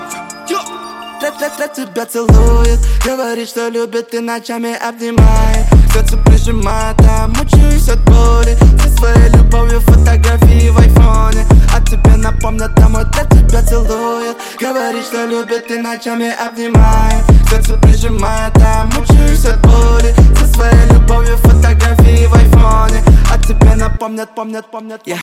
• Качество: 128, Stereo
рэп